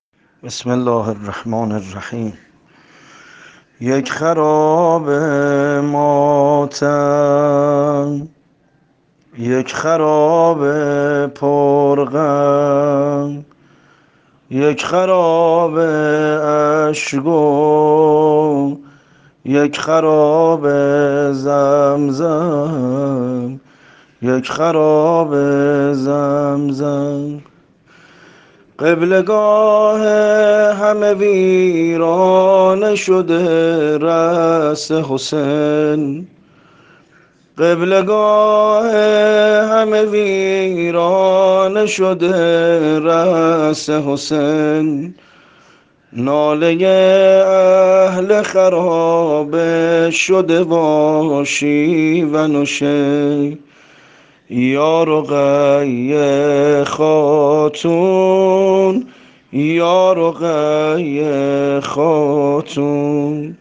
سبک : مکن ای خار هراس ...